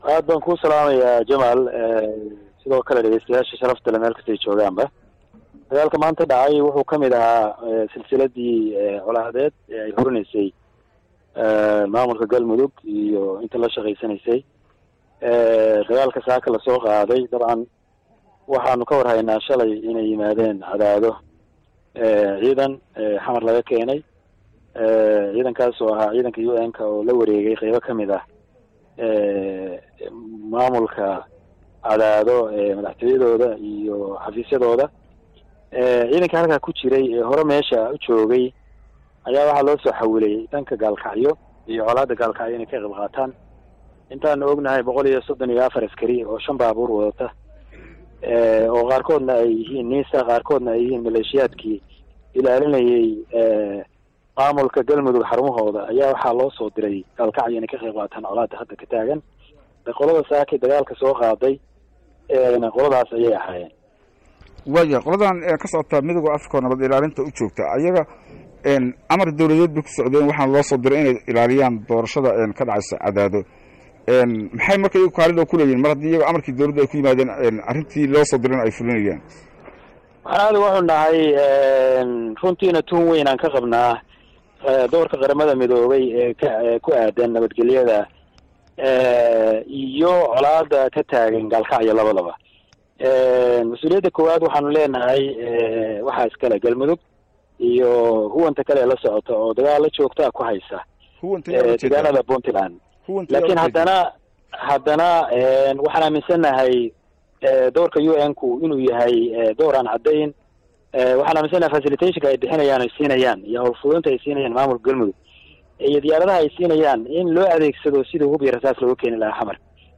25 okt 2016 (Puntlandes)  Wasiirka Deegaanka, Duurjoogta iyo Dalxiiska Puntland Dr Cali Cabdulaahi Warsame oo waraysi siiyey Radio Daljir ayaa ka hadlay Duulaanka lagu hayo magaalaga Gaalkacyo ee xarunta Gobolka Mudug  isagoo uga baaqay UN ka inay caddeeyaan doorkooda dagaalkaas maadaama ay hawl fududayn u sameeyaan huwanta ka kooban DFS iyo maamulka Galmudug.